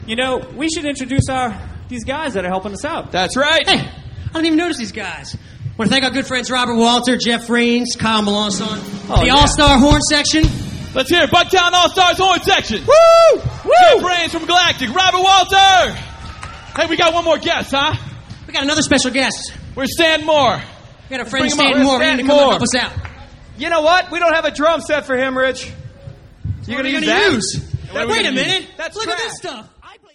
Funk
Kids
Pop
Rock